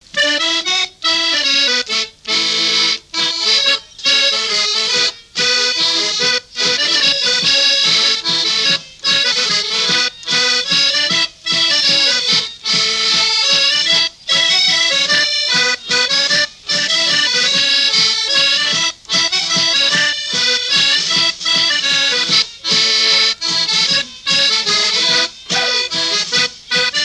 Saint-Pierre-du-Chemin
danse : mazurka
Pièce musicale inédite